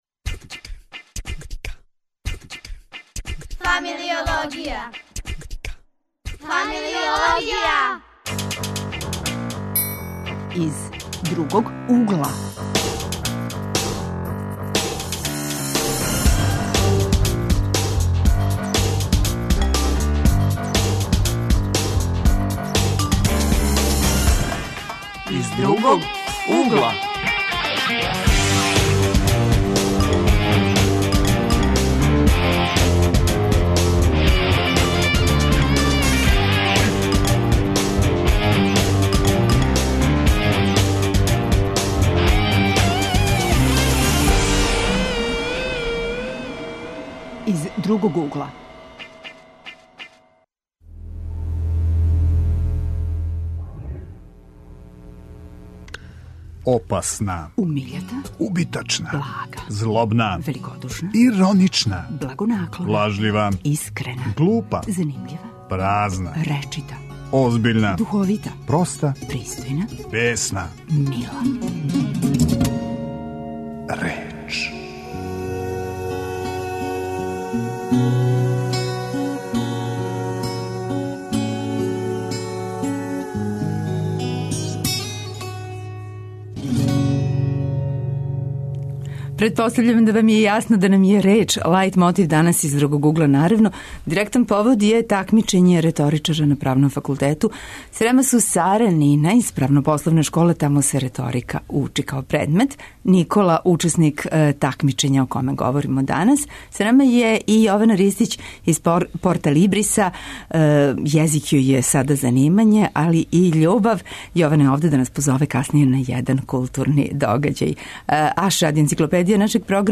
Гости су учесници такмичења.